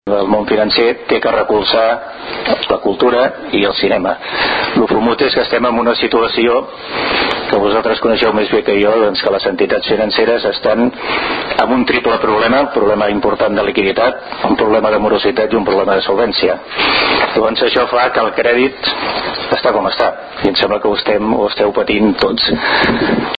Intervencions durant la roda de premsa
Tall de veu